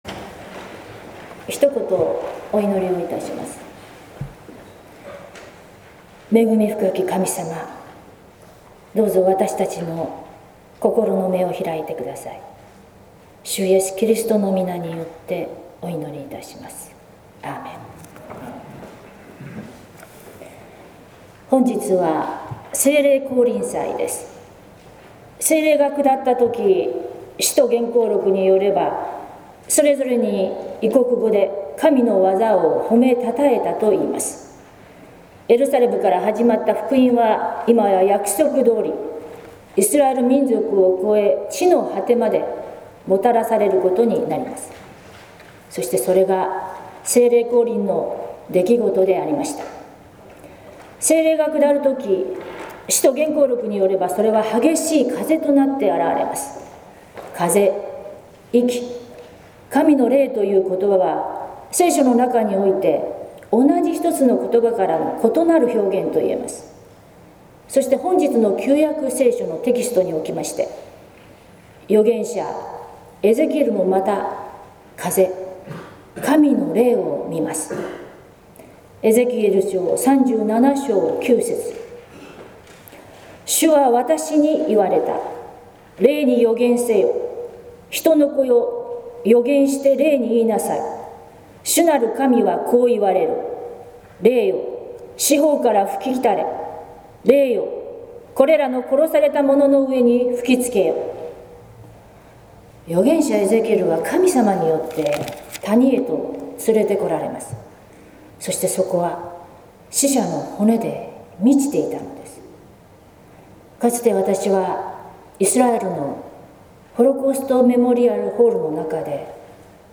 説教「渇いた心に、花は咲きますか」（音声版） | 日本福音ルーテル市ヶ谷教会
聖霊降臨祭